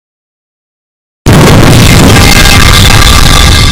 bass boosted metal pipe
bass-boosted-metal-pipe.mp3